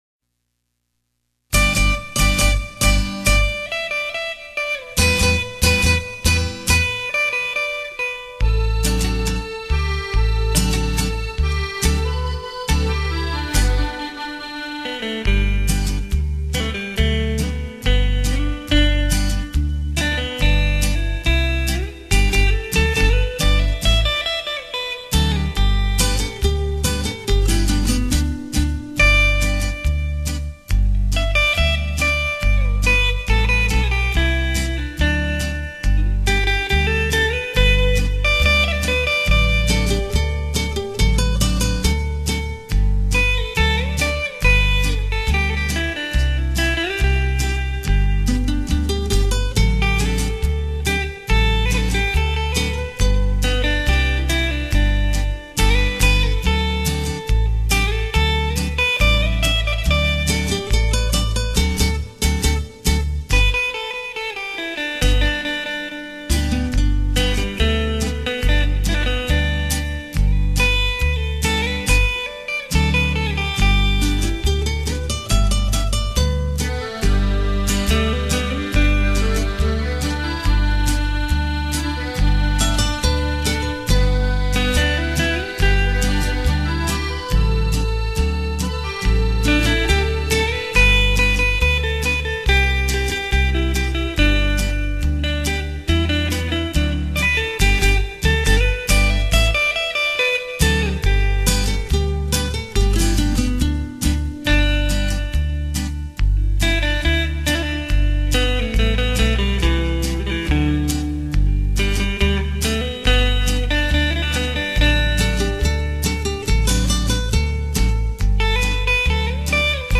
吉他的乐声，从不曾消失在你我的生命中